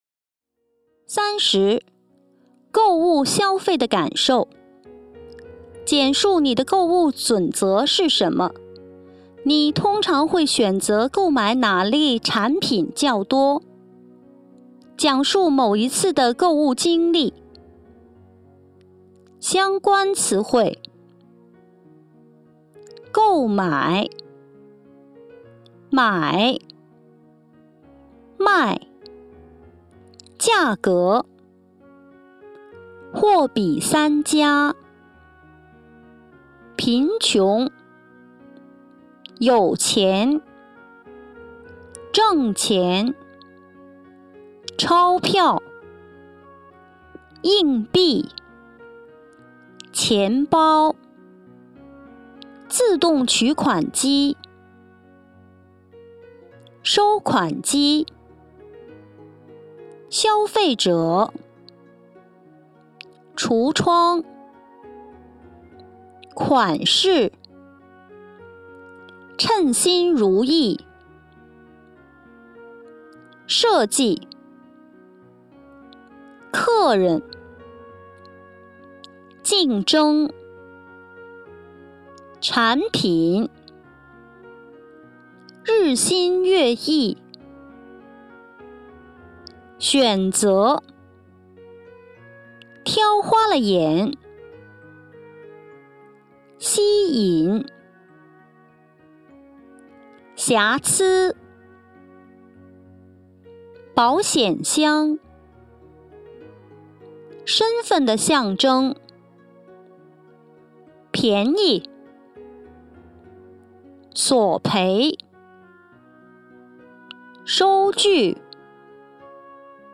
第三十題 《購物（消費）的感受》語音參考